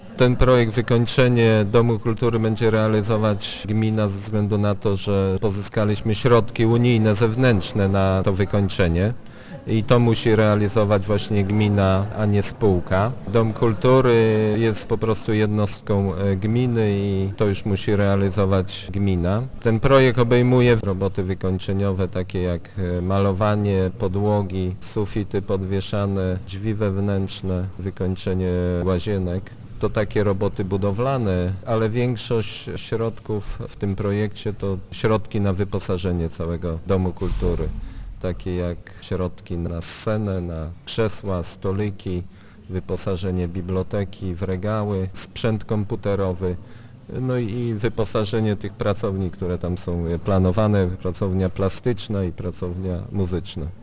Wójt Jacek Aanasiewicz tłumaczy, że zdecydowano się na takie rozwiązanie z powodów formalnych i finansowych: